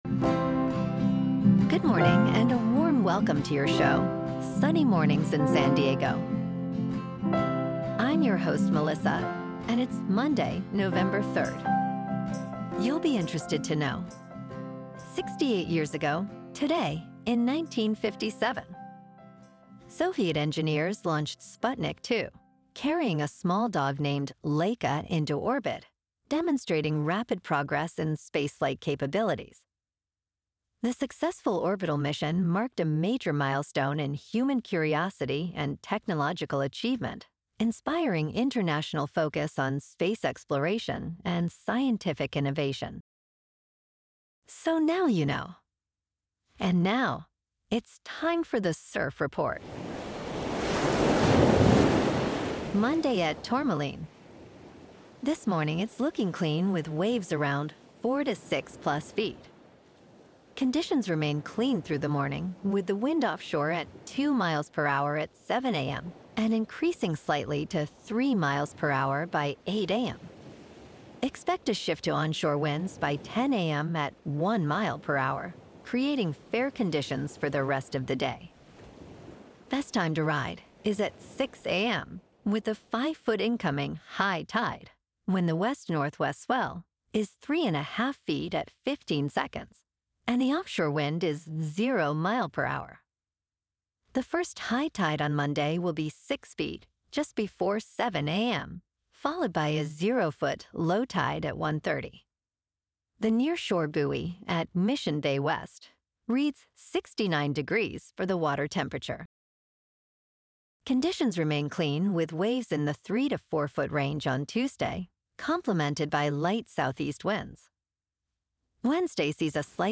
Daily San Diego News, Weather, Surf, Sports.
The #1 Trusted Source for AI Generated News™